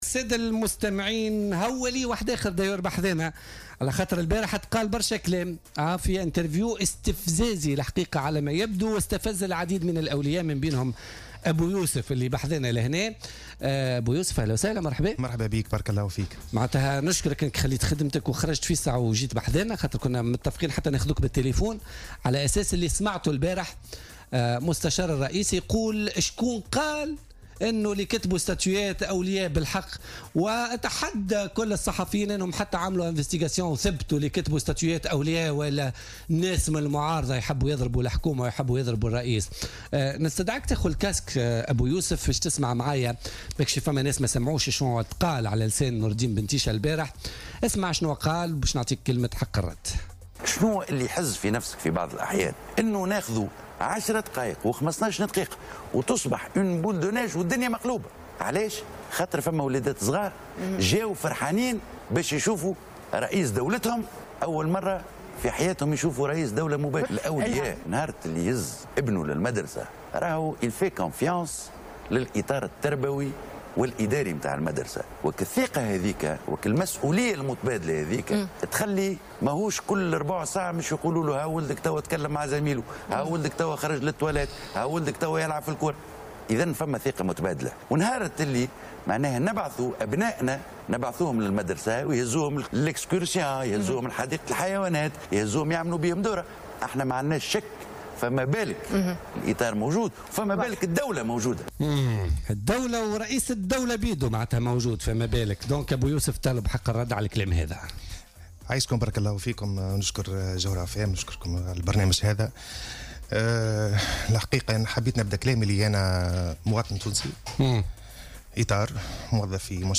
عبر مواطن من منطقة زاوية سوسة اليوم الجمعة لدى حضوره في برنامج بولتيكا عن استيائه الشديد من اخراج ابنه التلميذ بالمدرسة النموذجية بسوسة يوم الأربعاء الماضي من قسمه لإستقبال رئيس الجمهورية دون علمه.